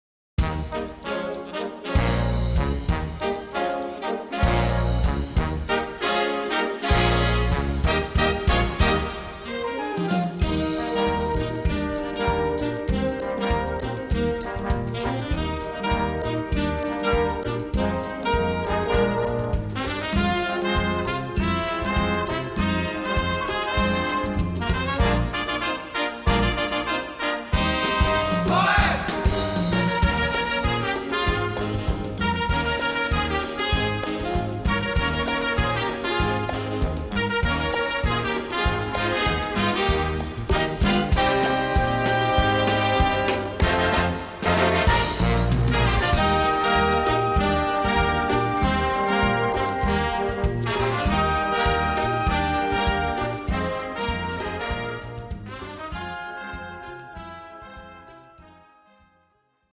Gattung: Jazz
A4 Besetzung: Blasorchester Zu hören auf